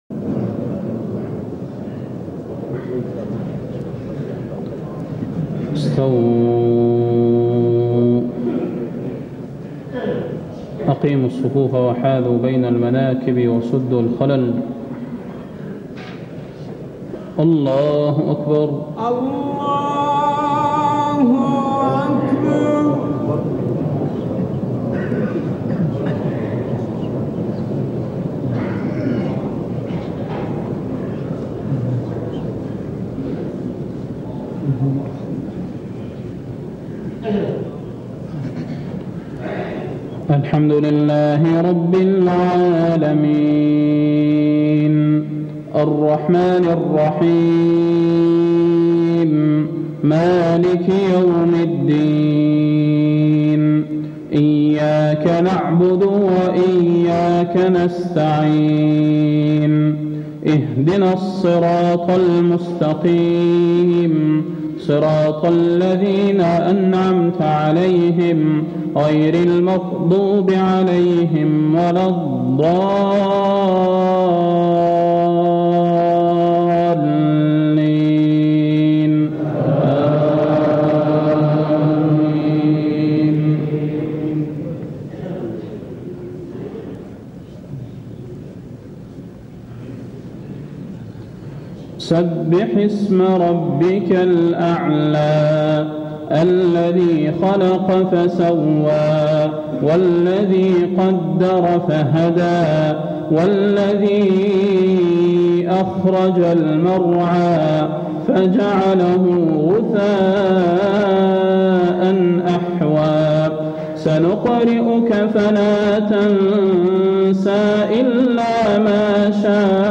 صلاة المغرب - ذوالقعدة 1421هـ سورتي الأعلى و التين > 1421 🕌 > الفروض - تلاوات الحرمين